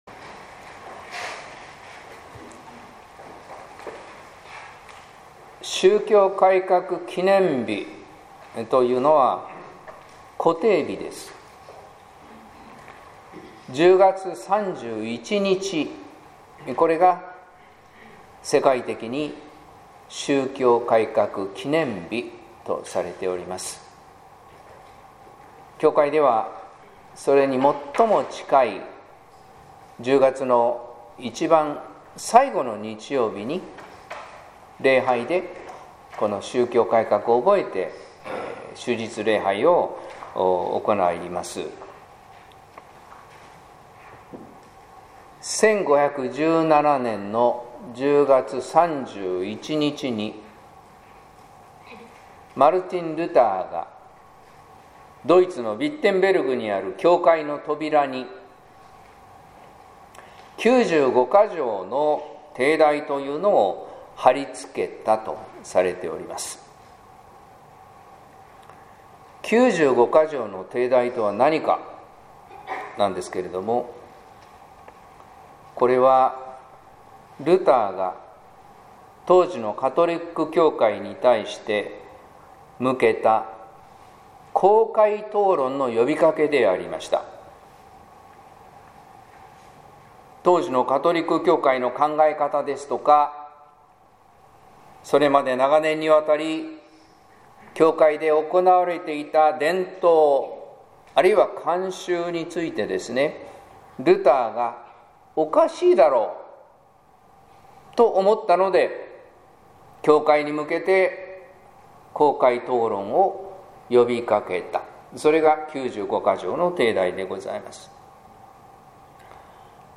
説教「揺るぎなきルター」（音声版）